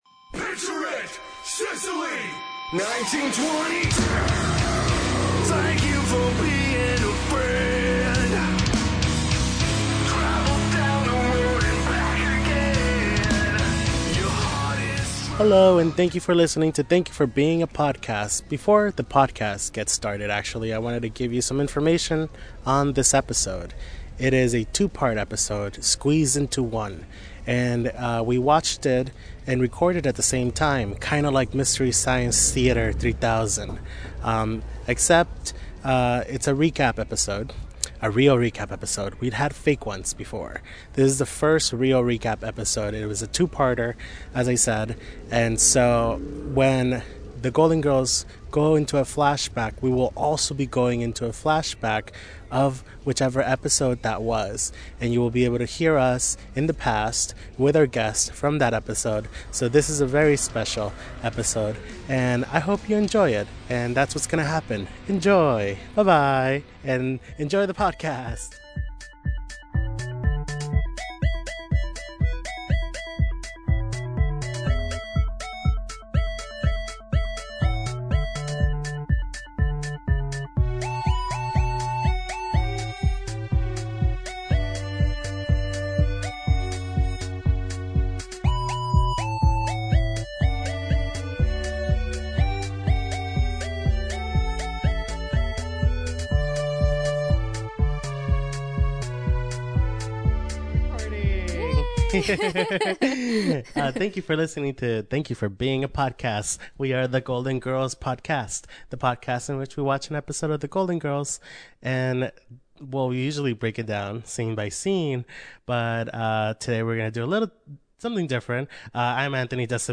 This is the first real clip episode and it's a double episode! This week, we decided to record and watch both episodes simultaneously for the first time, kind of like MST3K.